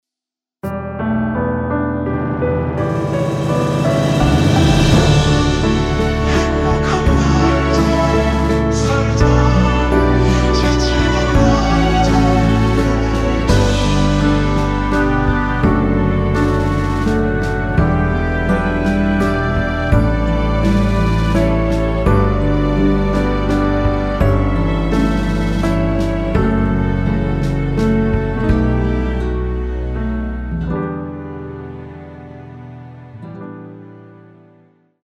이곡의 코러스는 미리듣기에 나오는 부분이 전부 입니다.다른 부분에는 코러스가 없습니다.(미리듣기 확인)
원키에서(-6)내린 코러스 포함된 MR입니다.
앞부분30초, 뒷부분30초씩 편집해서 올려 드리고 있습니다.
중간에 음이 끈어지고 다시 나오는 이유는